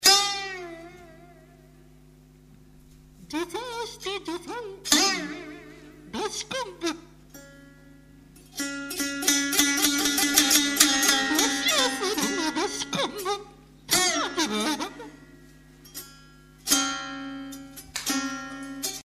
a collaboration of Senryu-butoh-dance and music.
Voice, Recorder and Percussions
Theremin and Electric instruments